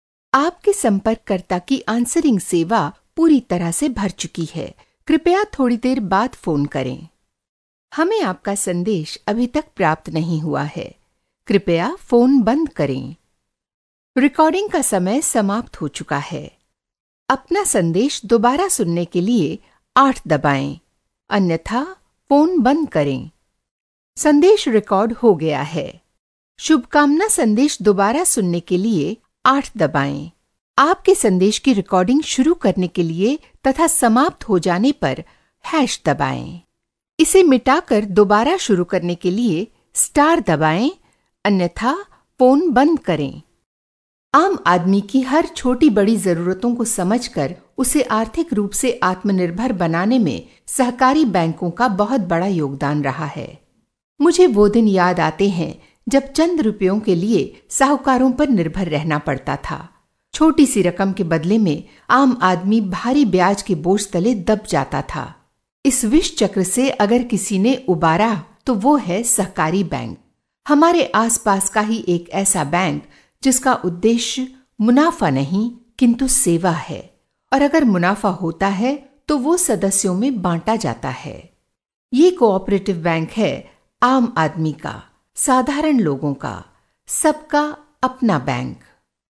indian female voice over artist
Sprechprobe: Werbung (Muttersprache):